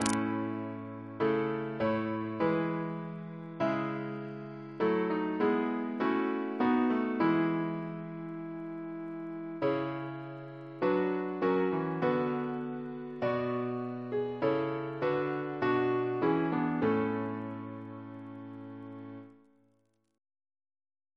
Double chant in A Composer: Henry Smart (1813-1879) Reference psalters: ACB: 262; ACP: 108